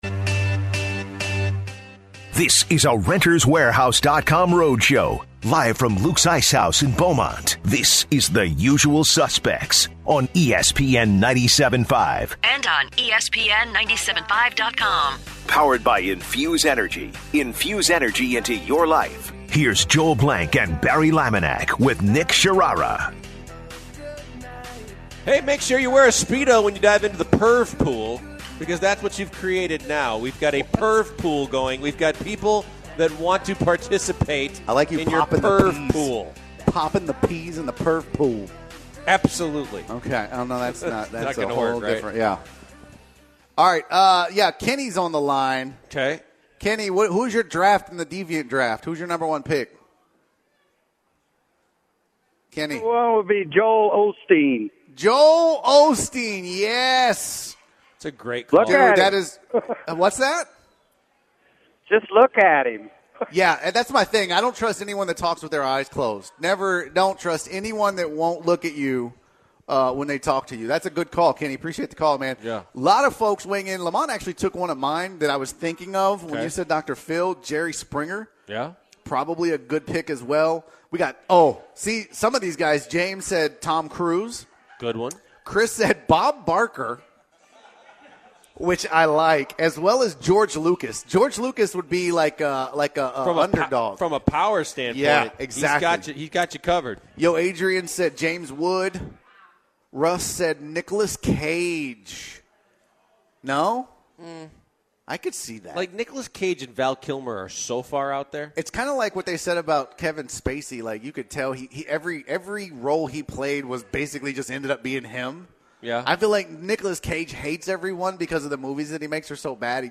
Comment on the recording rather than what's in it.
which kicks off a flood of listener phone calls and conversations.